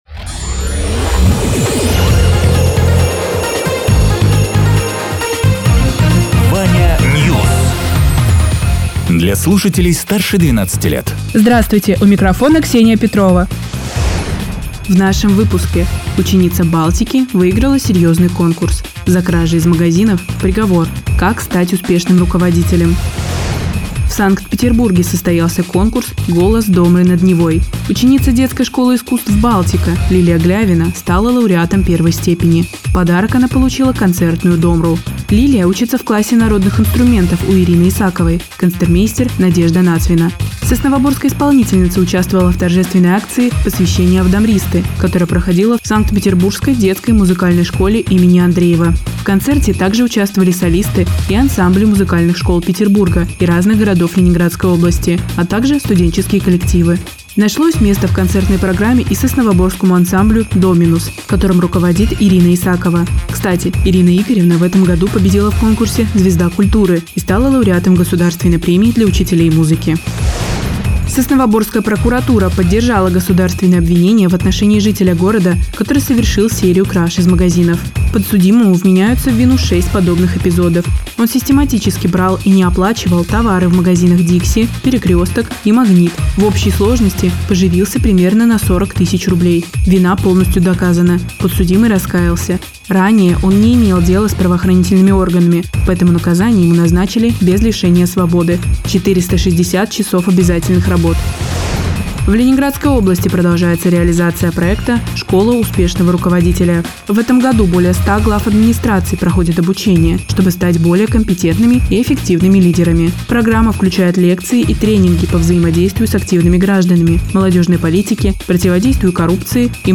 Радио ТЕРА 12.04.2026_12.00_Новости_Соснового_Бора